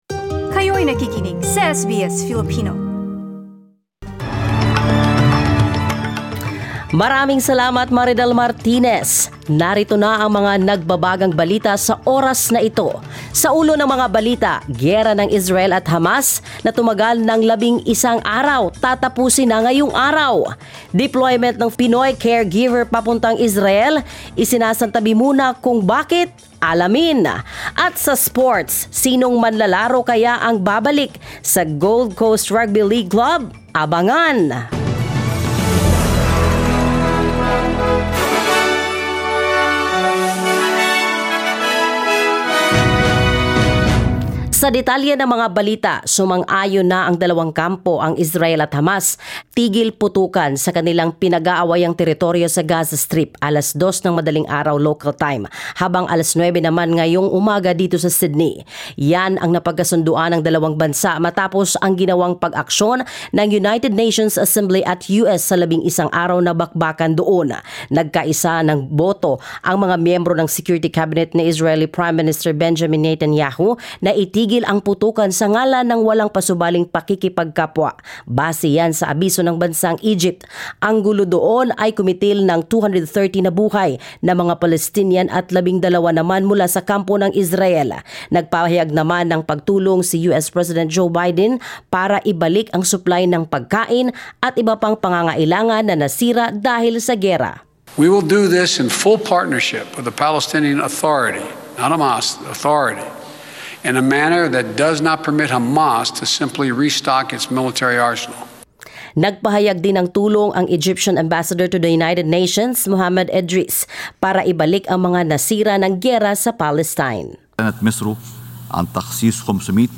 SBS News in Filipino, Friday 21 May